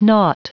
Prononciation du mot : naught